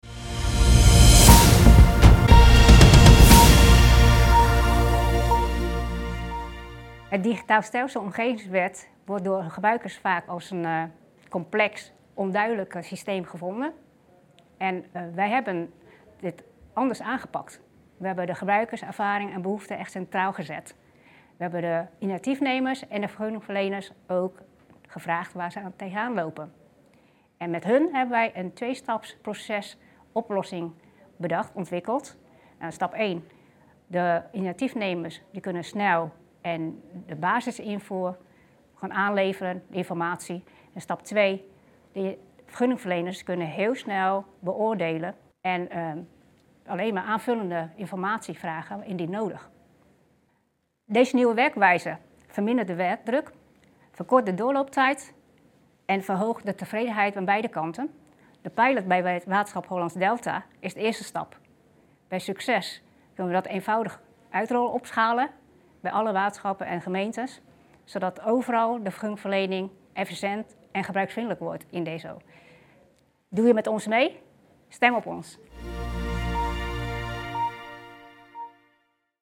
De pitch van Waterschap Hollandse Delta, een genomineerde van de Aandeslag-Vakpubliekstrofee 2025.